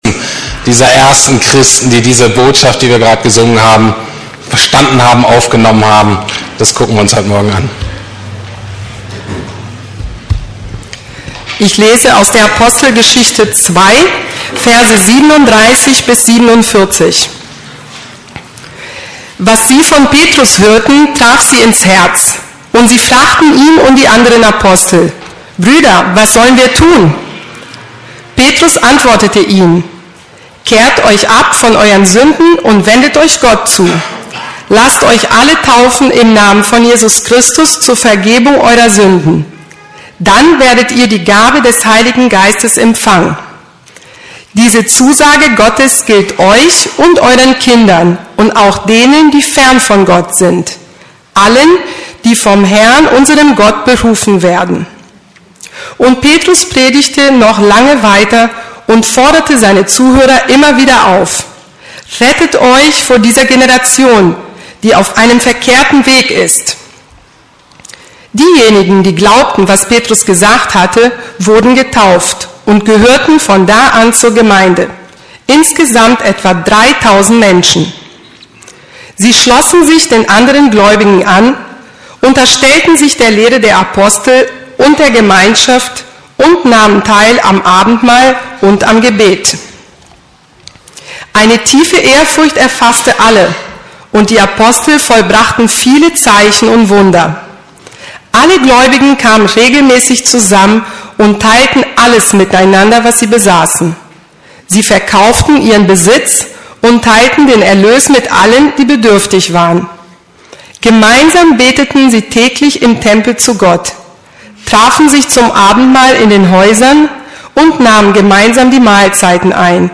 Unsere Berufung neu entdecken: Gemeinschaft ~ Predigten der LUKAS GEMEINDE Podcast